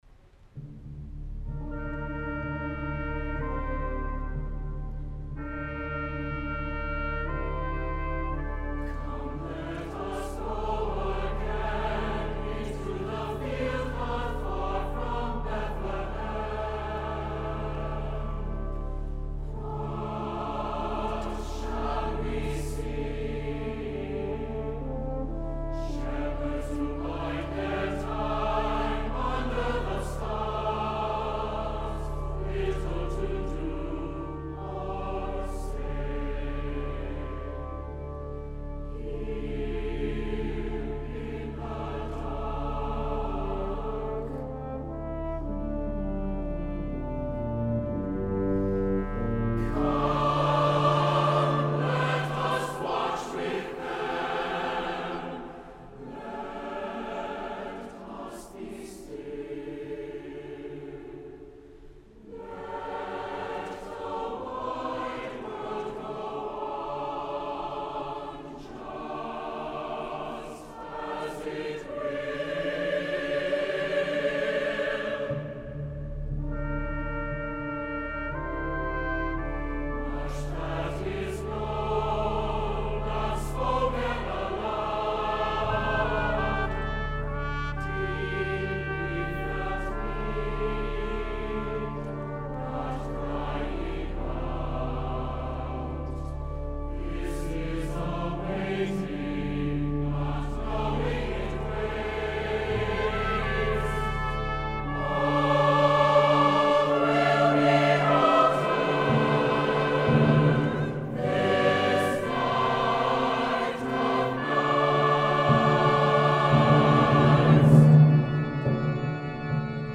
Voicing SATB, brass quintet, timp., organ